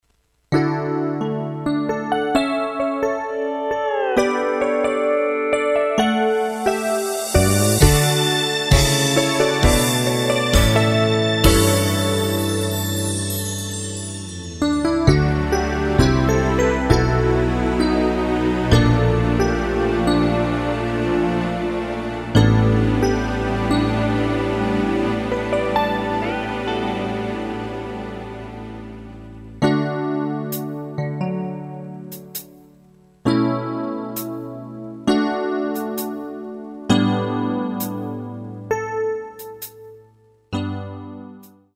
발매일 1997 키 D 가수
원곡의 보컬 목소리를 MR에 약하게 넣어서 제작한 MR이며